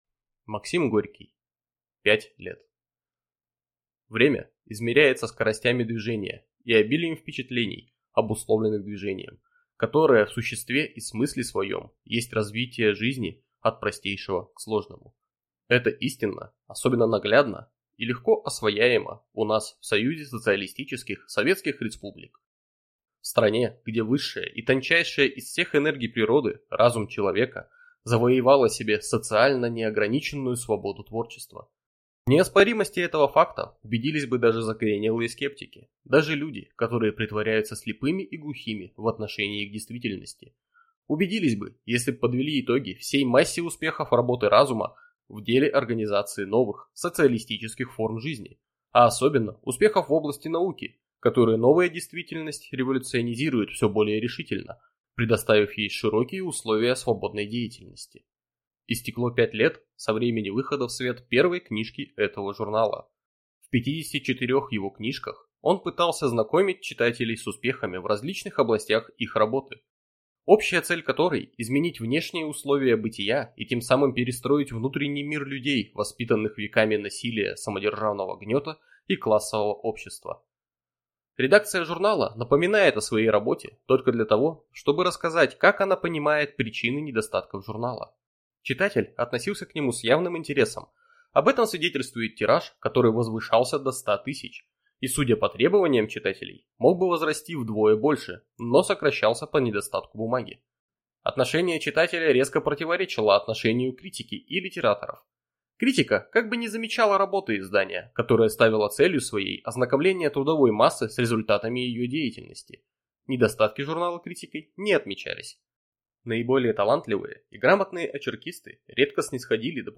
Аудиокнига Пять лет | Библиотека аудиокниг